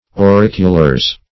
Search Result for " auriculars" : The Collaborative International Dictionary of English v.0.48: Auriculars \Au*ric"u*lars\, n. pl.